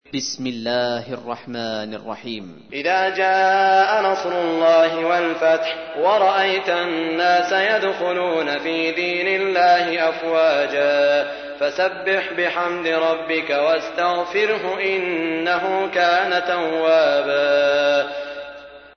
تحميل : 110. سورة النصر / القارئ سعود الشريم / القرآن الكريم / موقع يا حسين